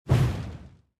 soceress_skill_flamevoltex_02_smallfire.mp3